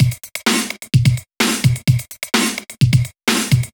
VMH1 Minimal Beats 13.wav